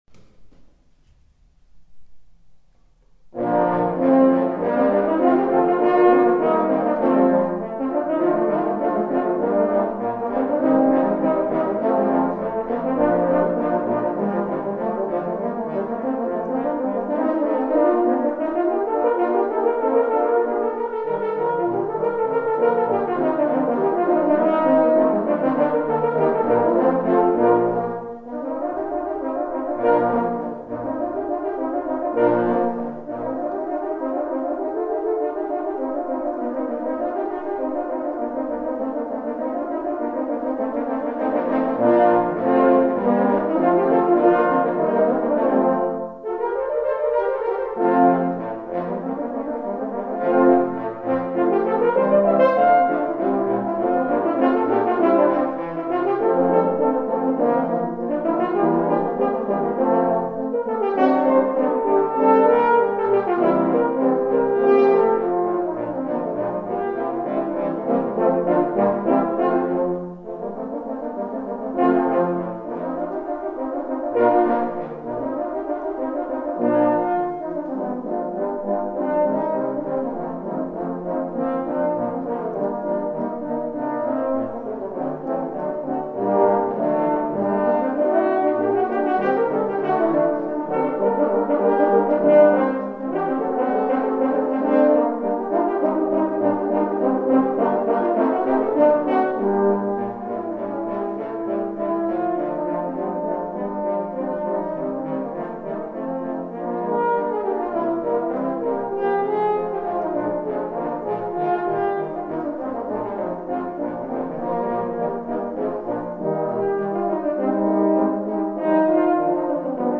Bearbeitung für Hornensemble
Besetzung: 10 Hörner
Arrangement for horn ensemble
Instrumentation: 10 horns